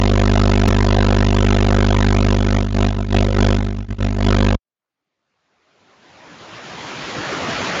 IS-136 uplink channel during a voice call
IS-136-Uplink.mp3